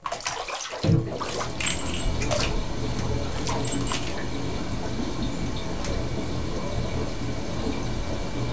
bath3.wav